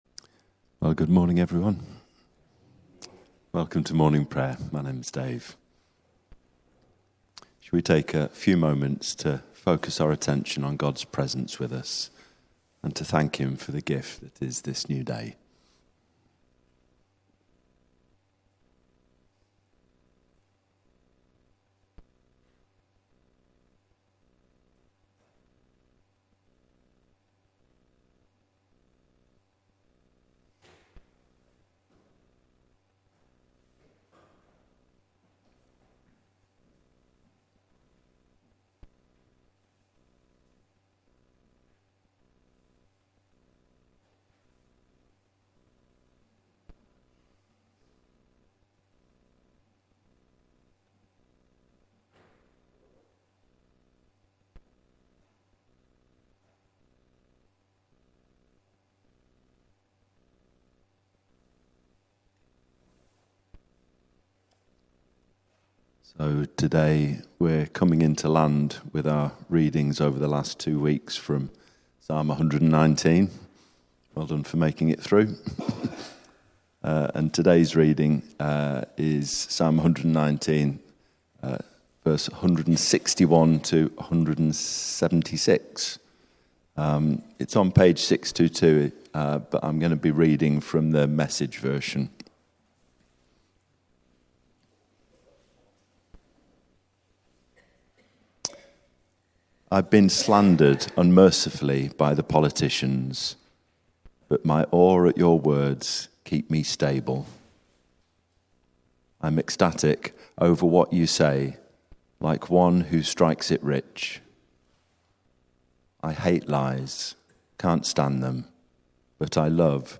Morning Prayer